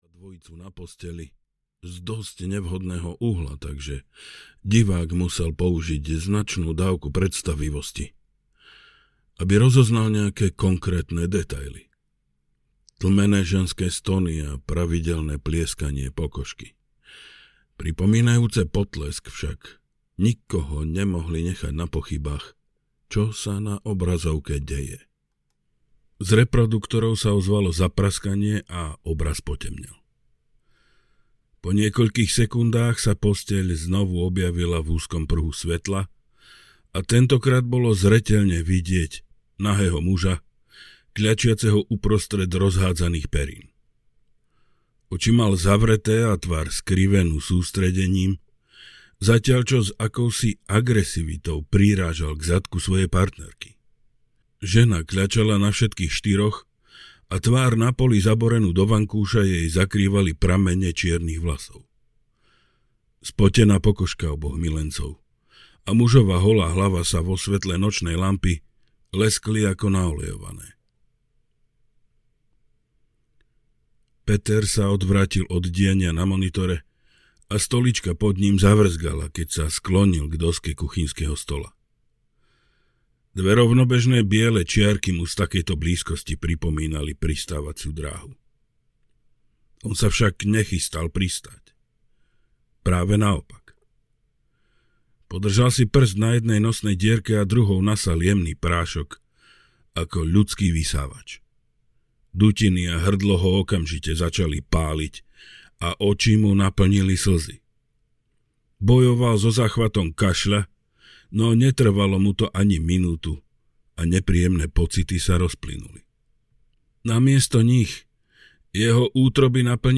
Zdochlinárka audiokniha
Ukázka z knihy